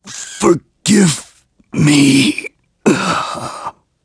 Clause_ice-Vox_Dead.wav